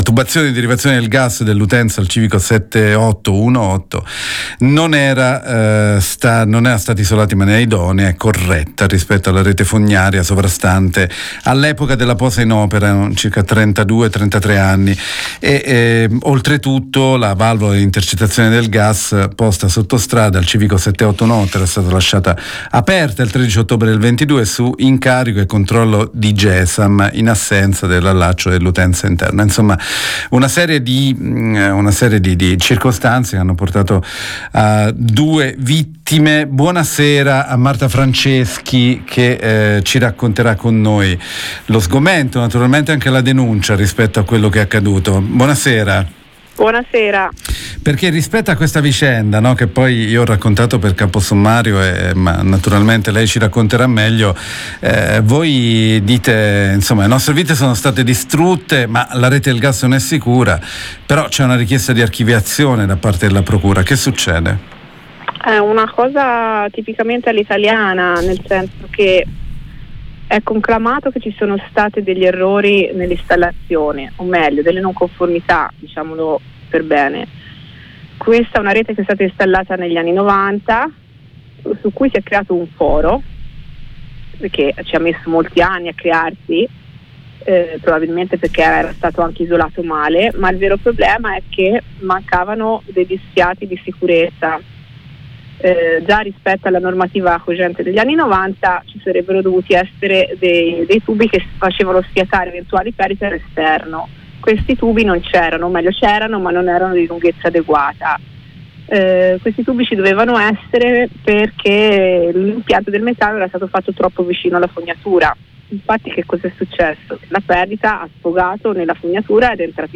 La procura ha chiesto il non luogo a procedere per 15 persone appartenenti a quattro diverse società. Intervista